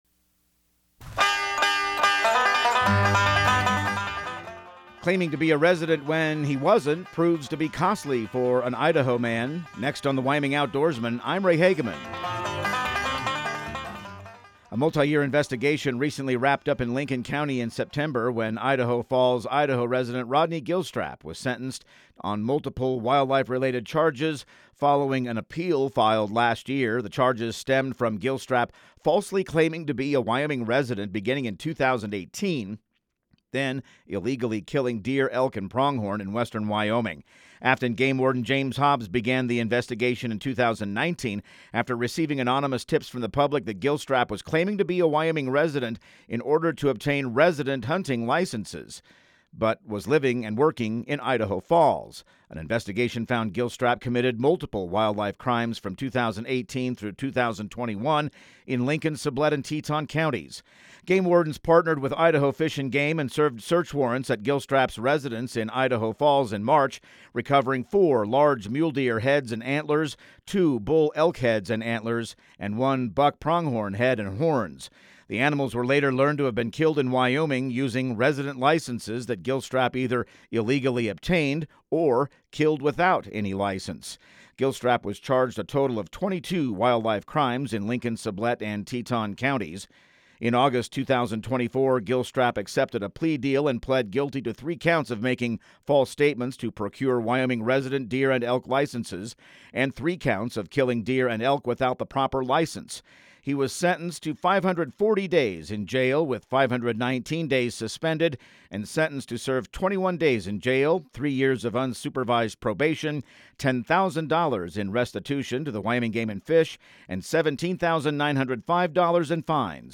Radio news | Week of November 10